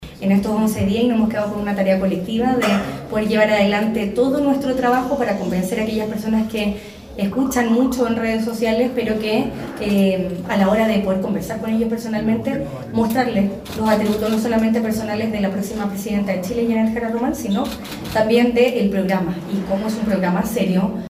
Por su parte, la alcaldesa de Viña del Mar, Macarena Ripamonti, aseguró que lo esencial en esta campaña de segunda vuelta, es poder explicar y convencer a los vecinos de que el programa de gobierno de la candidata es una propuesta seria.